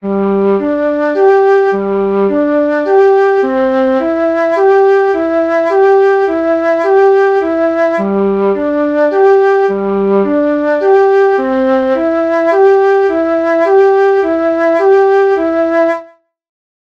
Así son os compases de 6/8 e de 4/4 a corcheas:
cambio_compas.ogg